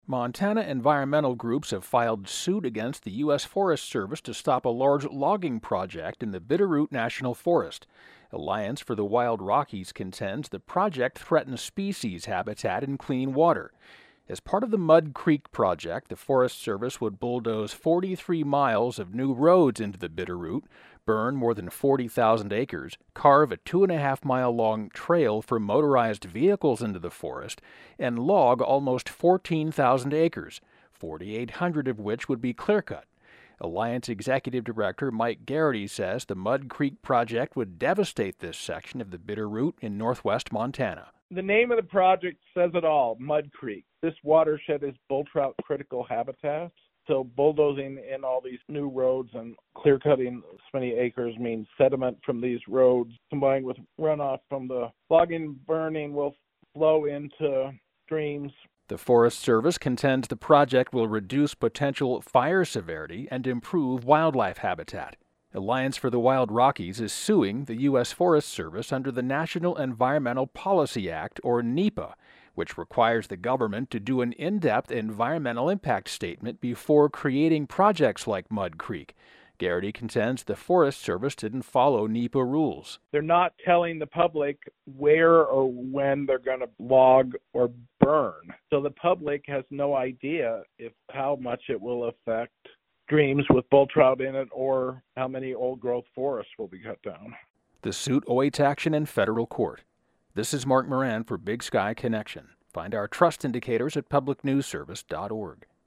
(Pronouncer: NEPA, "NEE-pah.")